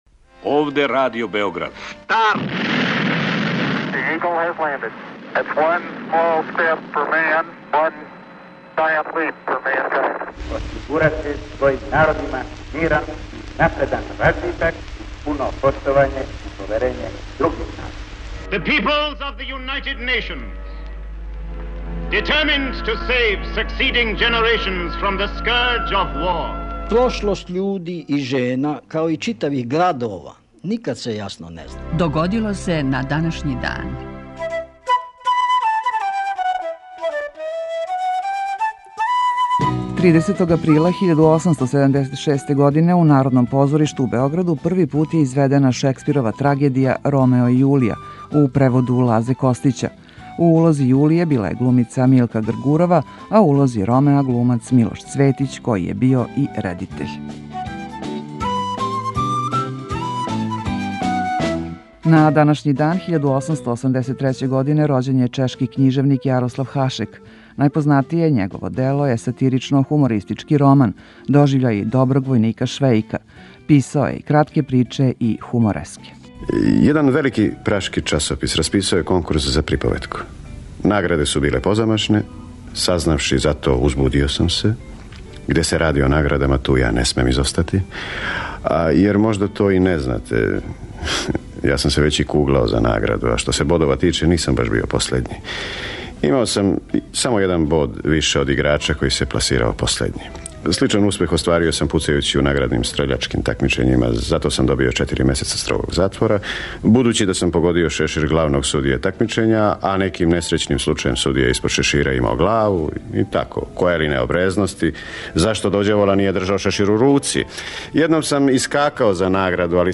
Емисија Догодило се на данашњи дан, једна од најстаријих емисија Радио Београда свакодневни је подсетник на људе и догађаје из наше и светске историје. У 5-томинутном прегледу, враћамо се у прошлост и слушамо гласове људи из других епоха.